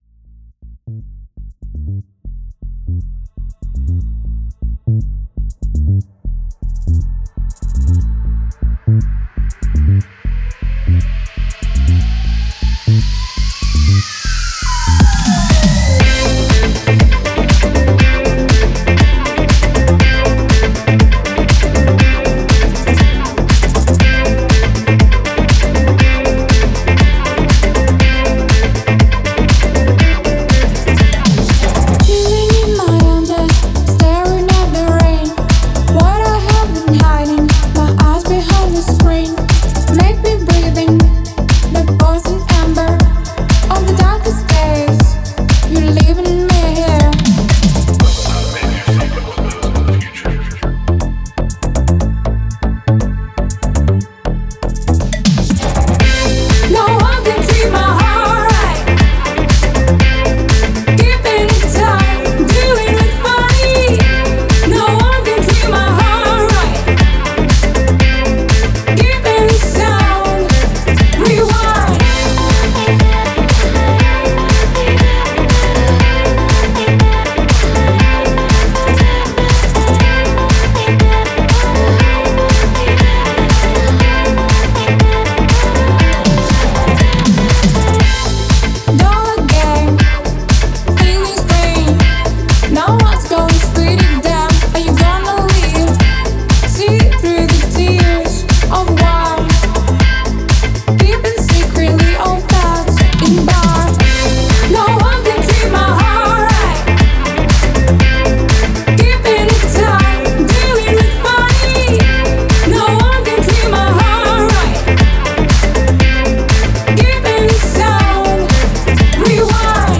Dance "NoOne"